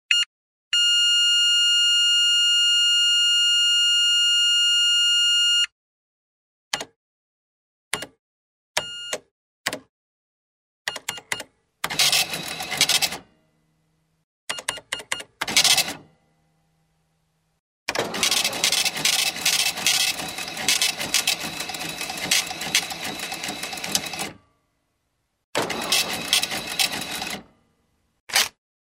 Electronic cash register, receipt printout
Sound category: Money, coins